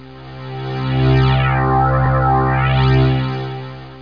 1 channel
snd_12709_HumWarp.mp3